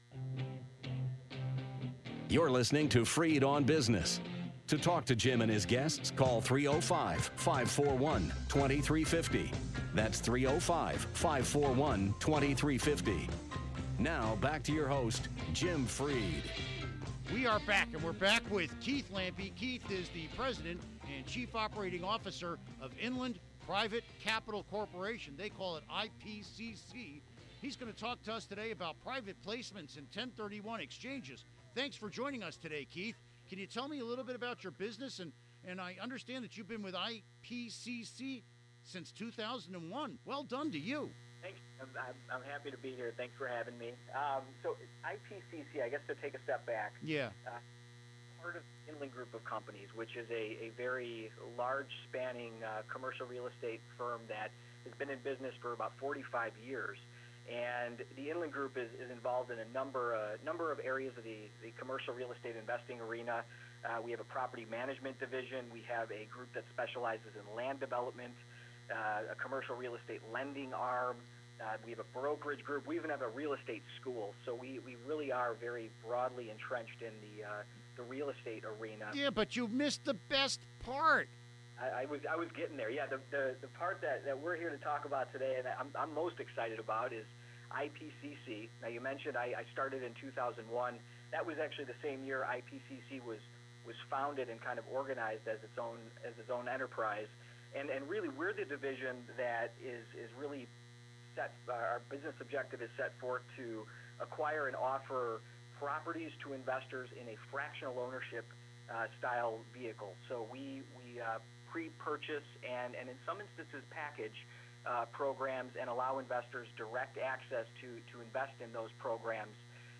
Interview Segment Episode 346: 12-10-15 (To download Part 1, right-click this link and select “Save Link As”.